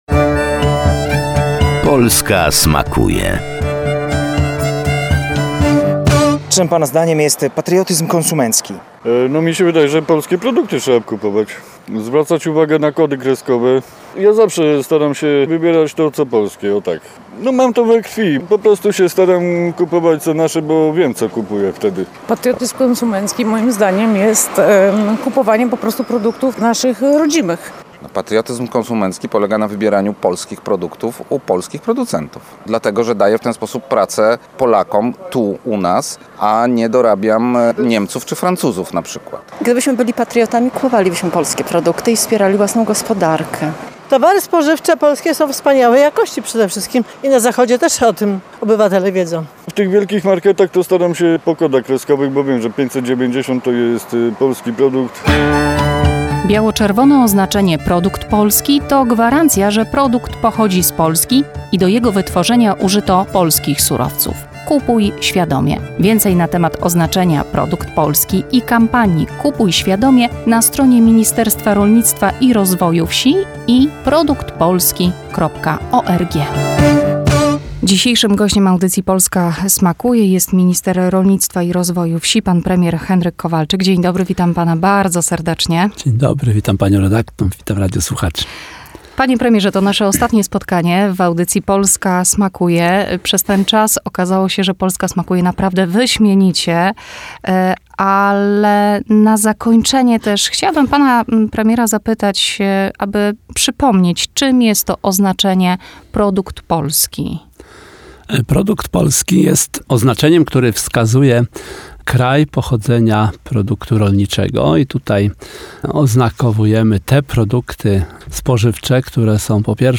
Polska smakuje – rozmowa z Premierem Henrykiem Kowalczykiem
Uczestnicy sondy przeprowadzonej na ulicach wiedzą czym jest patriotyzm konsumenckich i większość z nich robiąc zakupy wybiera rodzime produkty i dzięki temu wspomaga polską gospodarkę.